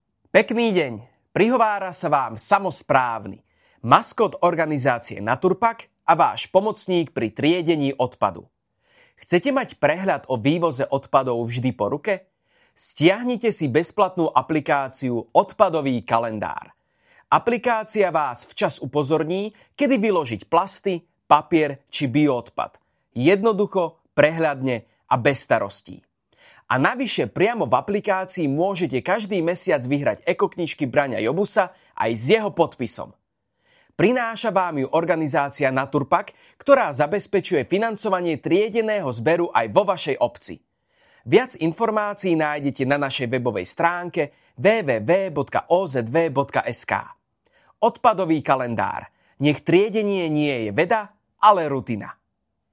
2. Rozhlasový spot – Verzia "Súťažná" (kratšia)
samo-spravny-odpadovy-kalendar-rozhlasovy-spot-2.mp3